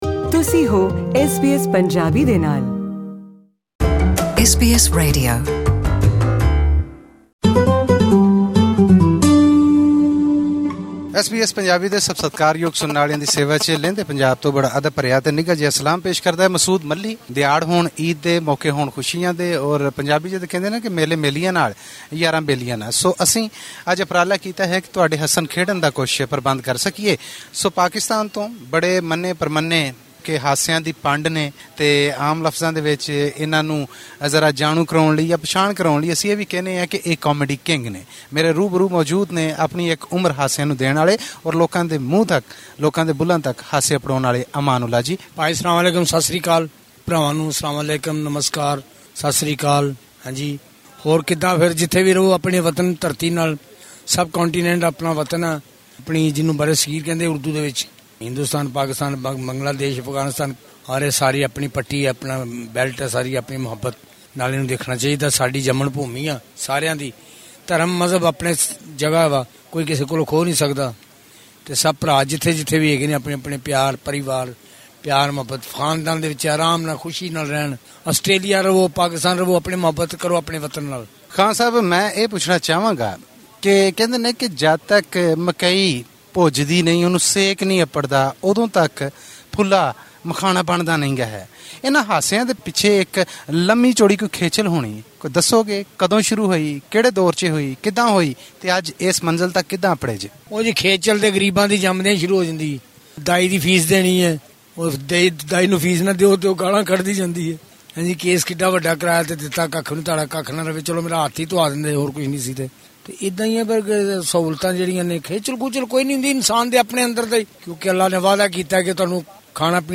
In this interview from 2018, listen to this man who made everyone who understood Punjabi, Urdu, Hindi, or simply the language of comedy, laugh.